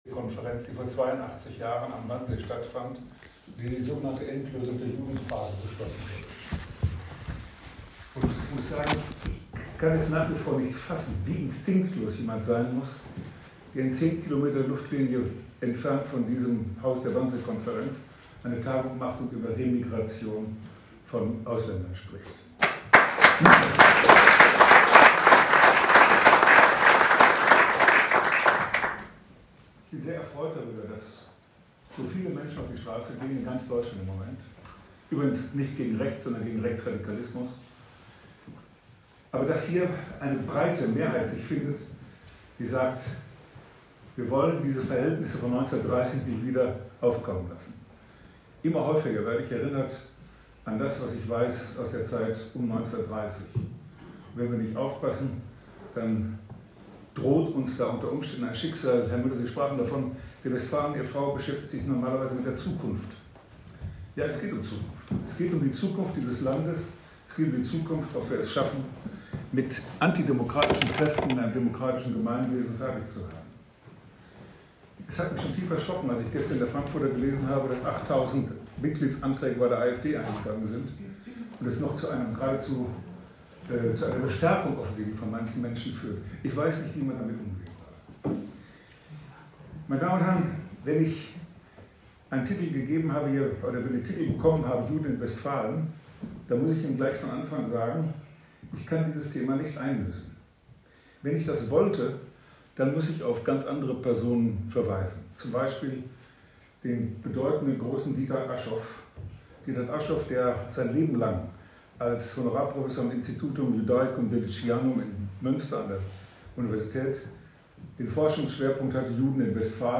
Bei einer Gemeinschaftsveranstaltung in der ehemaligen Drensteinfurter Synagoge am Sonntag (28. Januar) nach dem Internationalen Tag des Gedenkens an die Opfer des Holocausts mahnte Prof. Dr. Dr. Thomas Sternberg in seinem Vortrag vor etwa 60 Zuhörerinnen und Zuhörern vor dem Hintergrund aktueller demokratiefeindlicher und menschenverachtender Vorstellungen zur Wachsamkeit. Der Kampf gegen Antisemitismus sei „unsere ureigenste Sache“, denn nur so sei die Zukunft gestaltbar, befand der ehemalige Präsident des Zentralrats der Katholiken (ZdK). Die mit viel Beifall bedachte Rede ist hier zu hören.